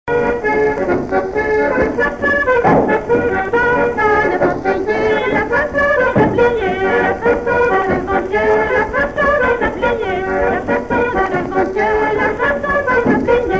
Chants brefs - A danser
branle : courante, maraîchine
Pièce musicale inédite